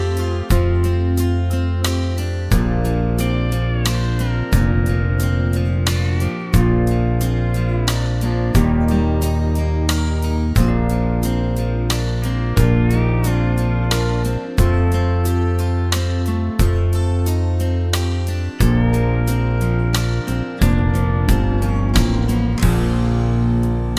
no Backing Vocals Country (Male) 2:56 Buy £1.50